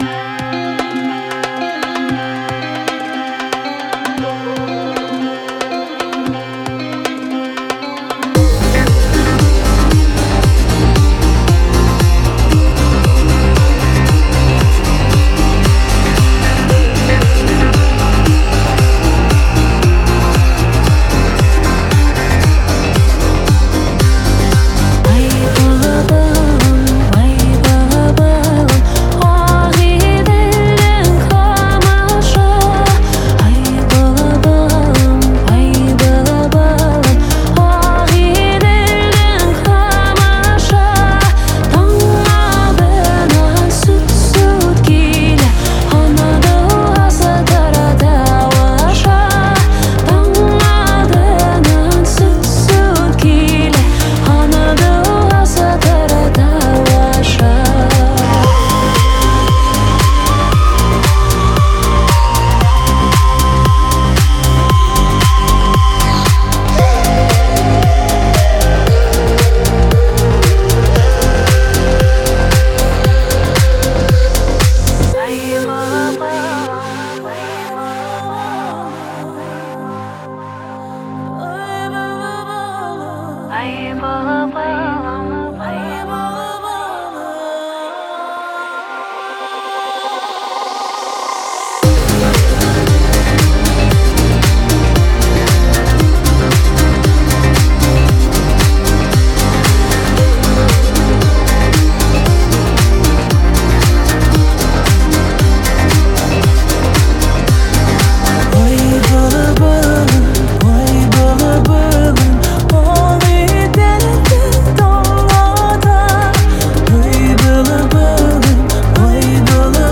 Жанр: Фолк